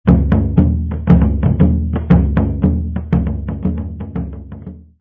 drum3-mp3cut.net_.mp3